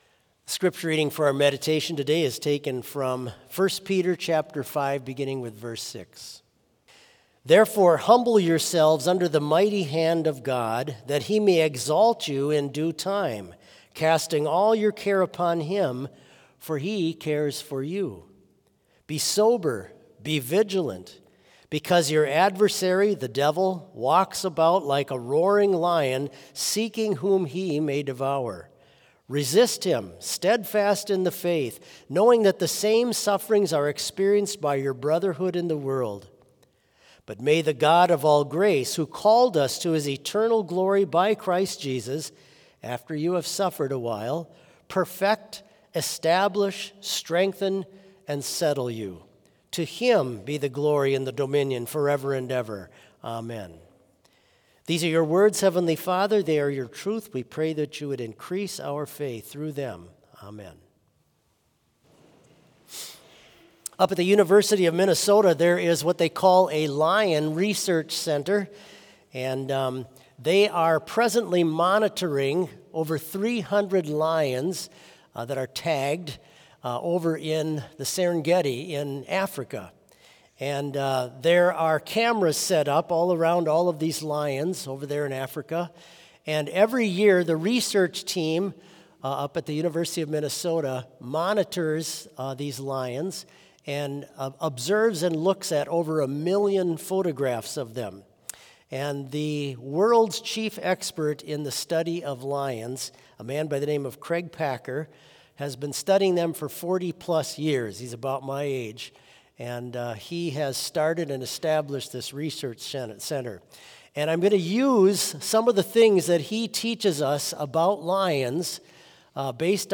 Complete service audio for Chapel - Monday, March 10, 2025